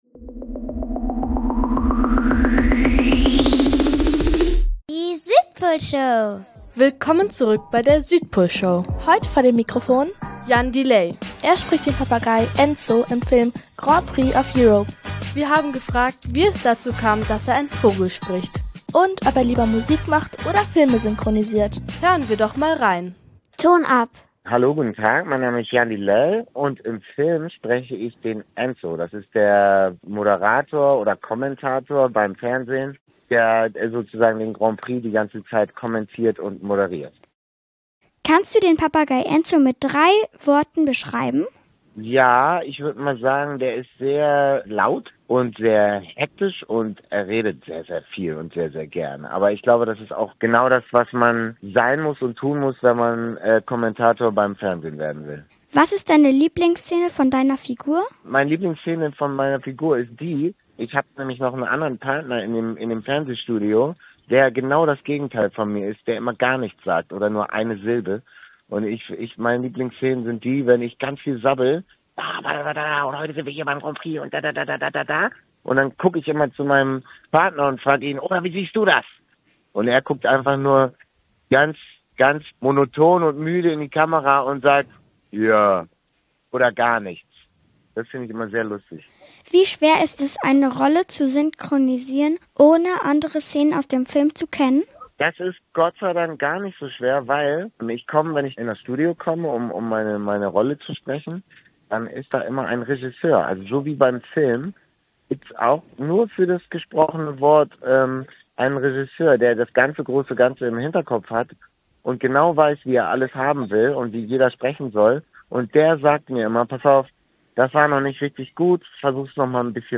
Interview mit Jan Delay | Filmtipp "Grand Prix of Europe"
Freut euch also auf ein lustiges Interview!